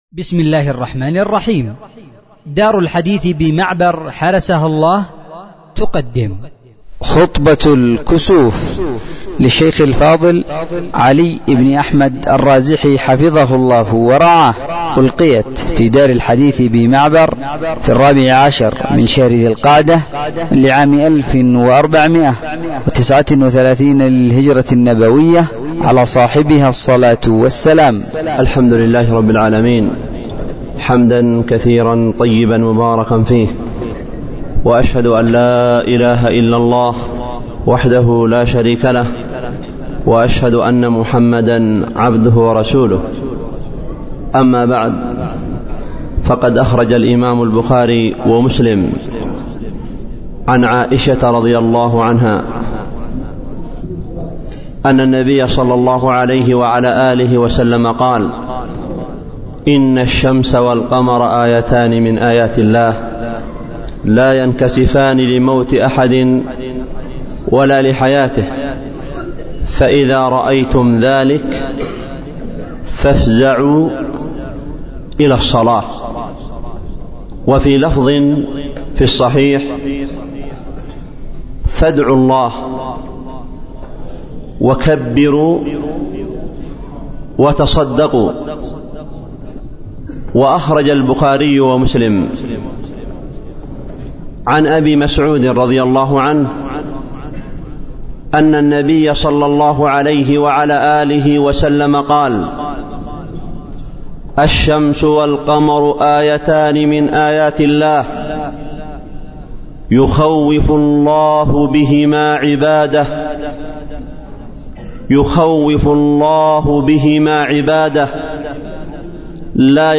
خطبة الكسوف في دار الحديث بمعبر حرسها الله تعالى